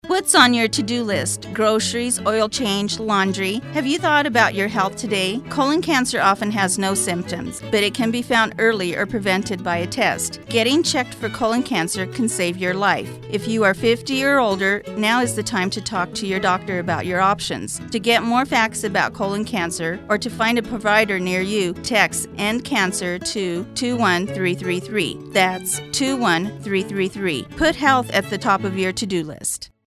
Radio Ads